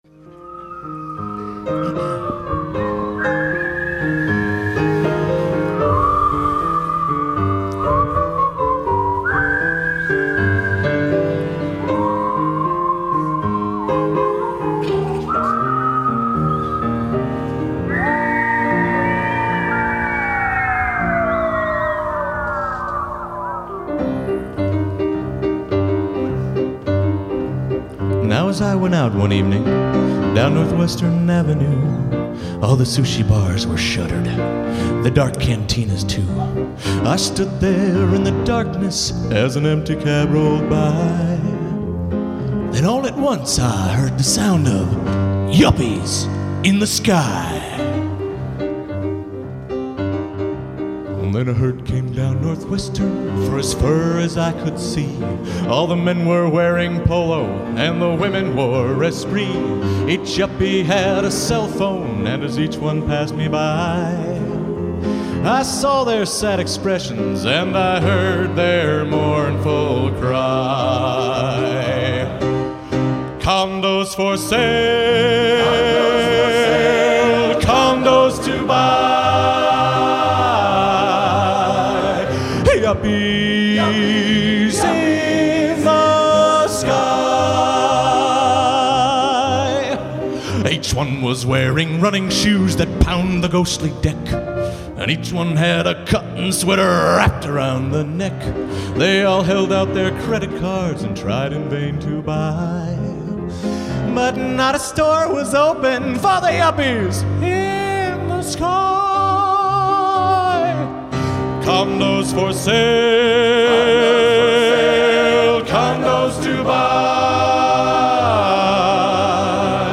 Genre: Humor/Parody | Type: